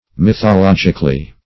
mythologically - definition of mythologically - synonyms, pronunciation, spelling from Free Dictionary
-- Myth`o*log"ic*al*ly, adv.